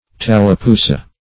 tallapoosa - definition of tallapoosa - synonyms, pronunciation, spelling from Free Dictionary